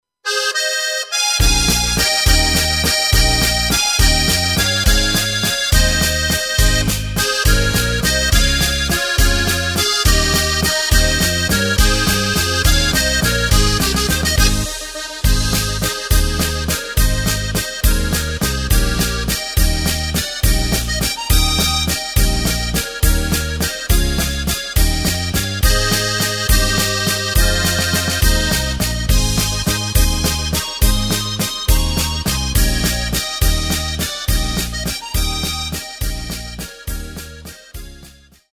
Takt:          3/4
Tempo:         208.00
Tonart:            Ab
Walzer Tradit.
Playback mp3 Demo